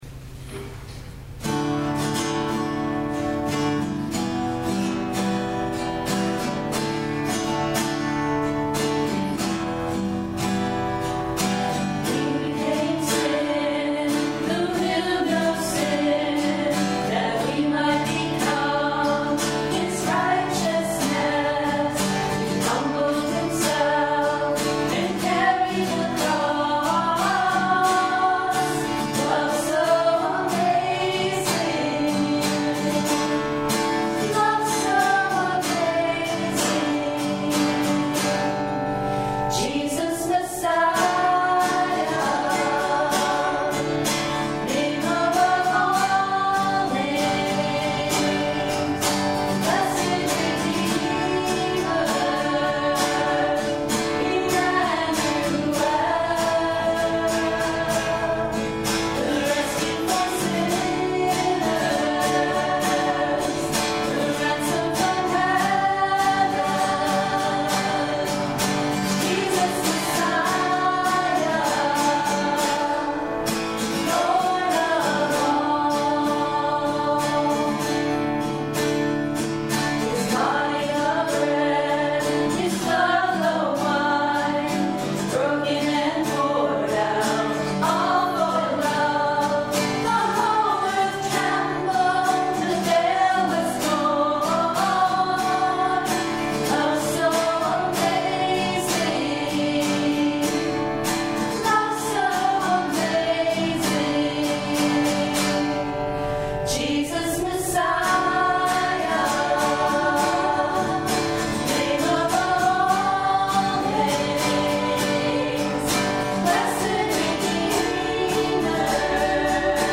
The Ascension of Our Lord, Christ Lutheran Church in Troy, NH (audio only)
Service Type: The Feast of the Ascension of Our Lord